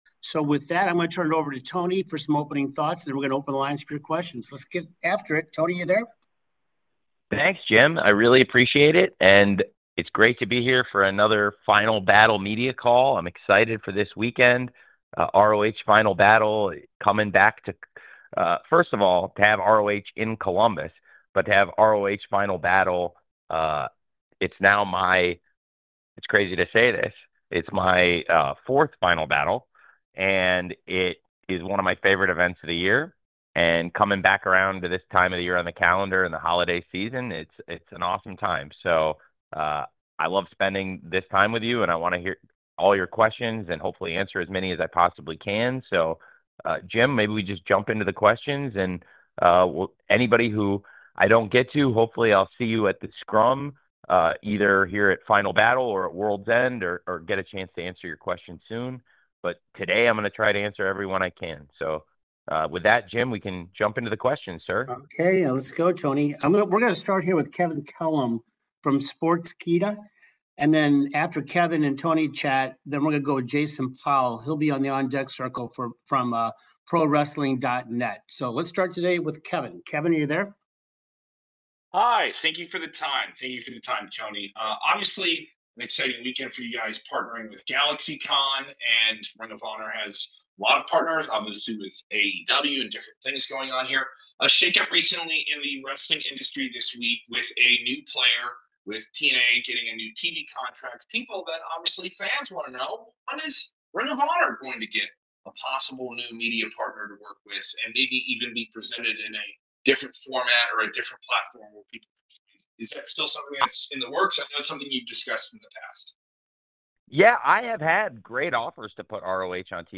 Khan was asked about that and more during his Final Battle pre-call Thursday, the audio of which can be found below.